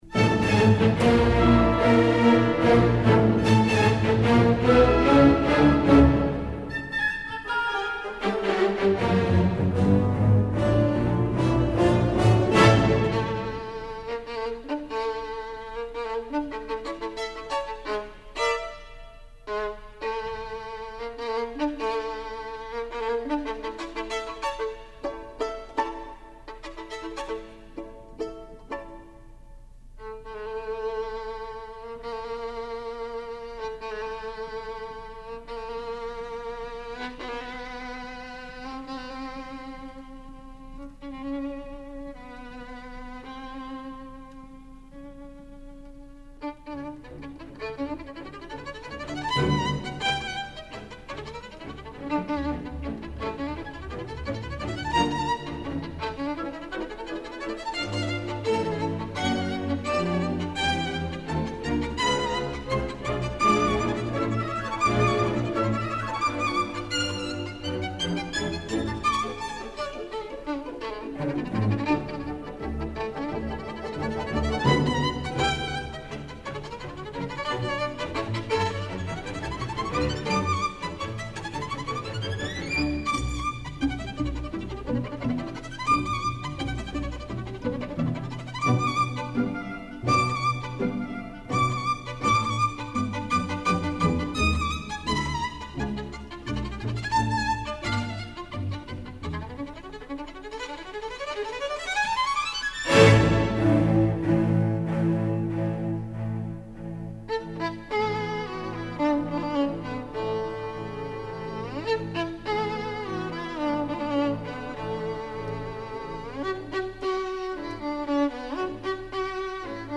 音樂類型：古典音樂
他以幹練、快速而著稱，作風霸氣而恢宏，技巧驚人而精確。